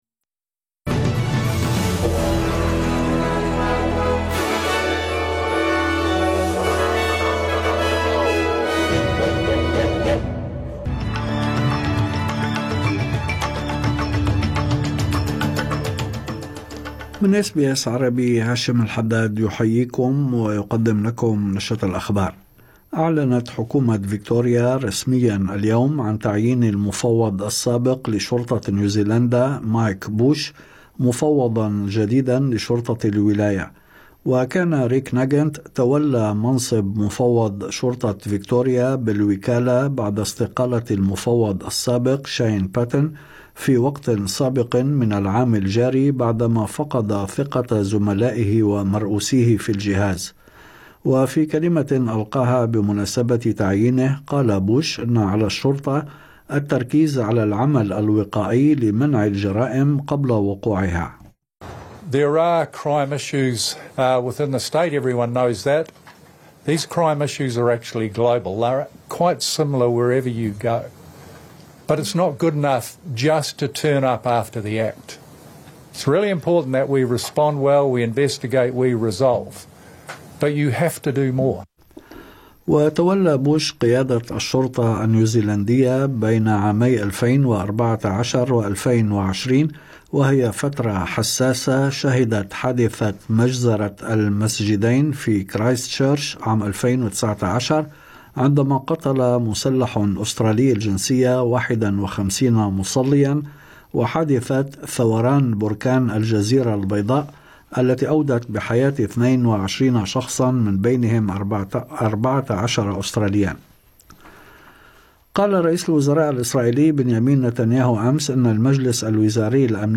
نشرة أخبار الظهيرة 6/5/2025